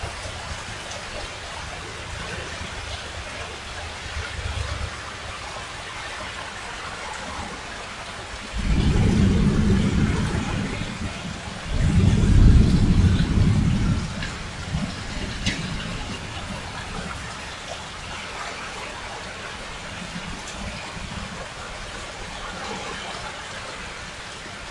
雨声1
描述：我的房间外面有雨声，AKG 414通过Apogee Duet。汽车不时通过.Wav文件在24位和96 kHz。
标签： 汽车 风暴 天气 自然 现场录音
声道立体声